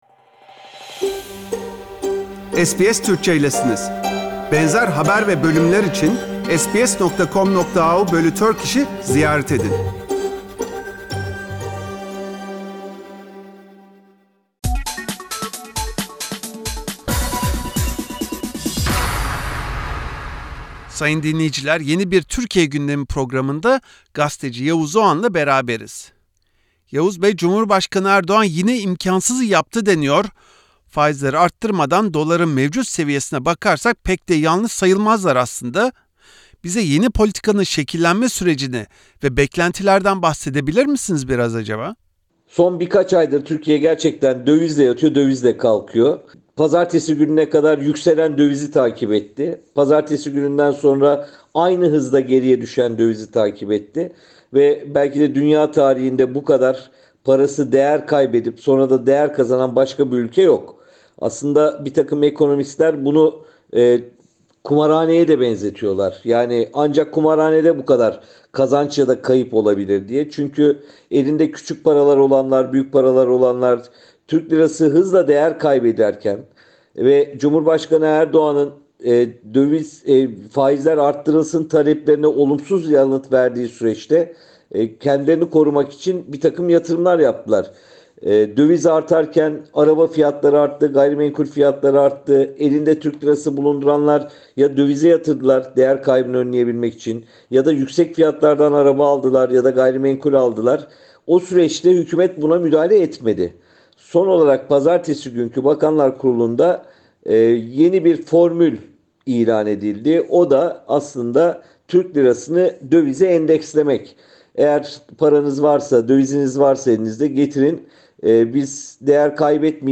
Gazeteci Yavuz Oğhan, Cumhurbaşkanı Erdoğan’ın beklenmedik kur hamlesi sayesinde TL’nin değer kazanması sonrası olanları ve Omicron varyantına karşı Türkiye’nin ne yaptığını SBS Türkçe için değerlendirdi.